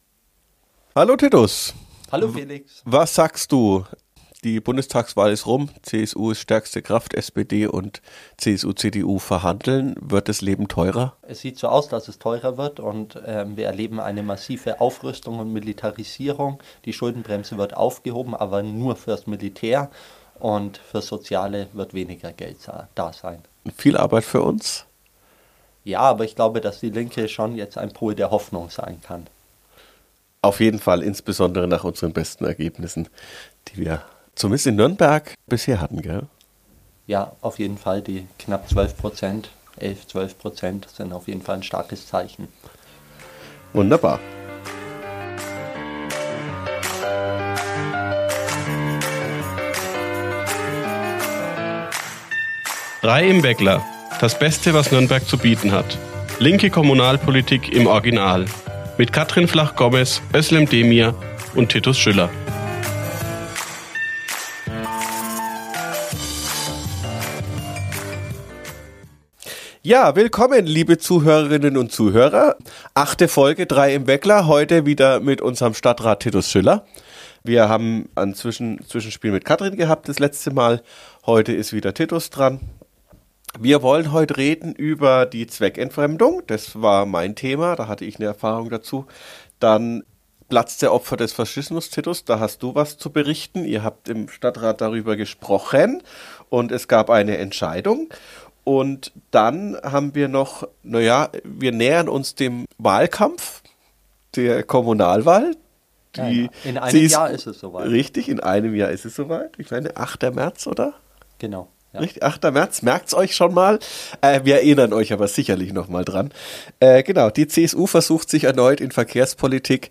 In dieser Folge sprechen wir mit unserem Stadtrat Titus Schüller über die Zweckentfremdungsverbotssatzung und den Wohnungsleerstand in Nürnberg. Außerdem gibt es Neues zur Gedenktafel am Platz der Opfer des Faschismus, die aktuelle Verkehrspolitik und den Wahlkampfvorstoß der CSU dazu.